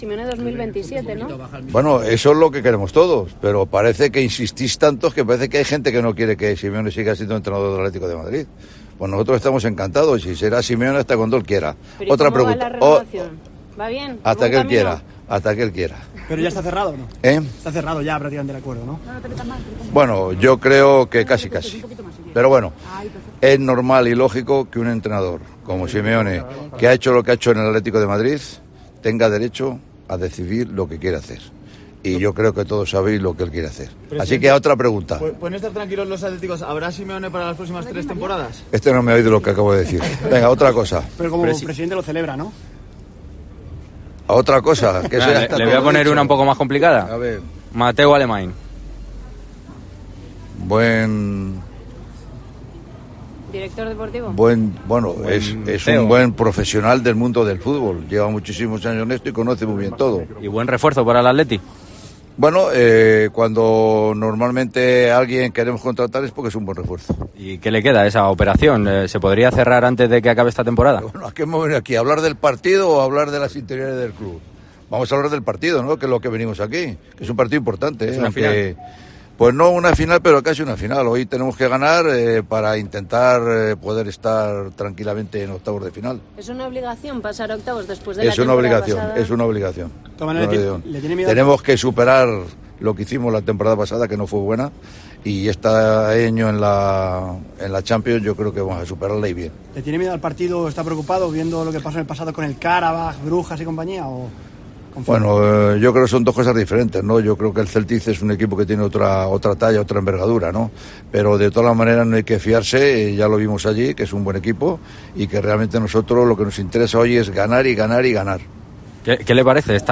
El presidente del Atlético de Madrid atendió a los medios y habló sobre la próxima renovación de Simeone: "Hasta que él quiera".
En la previa de este encuentro, el presidente del conjunto rojiblanco, Enrique Cerezo, atendió a los medios de comunicación y analizó la actualidad del equipo colchonero.